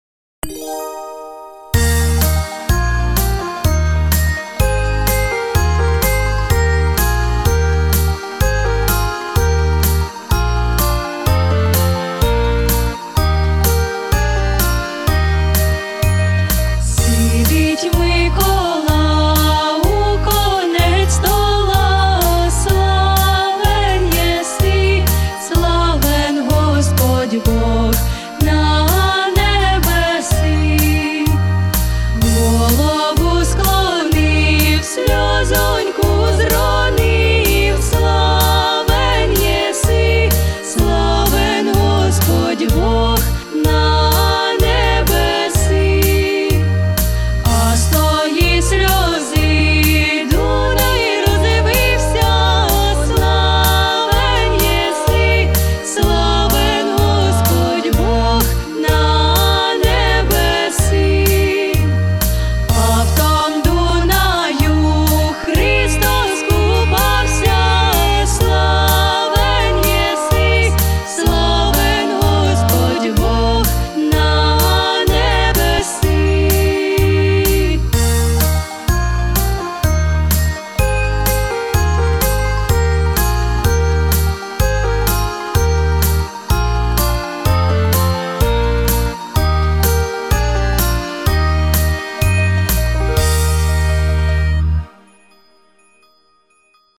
Всі мінусовки жанру Polka
Плюсовий запис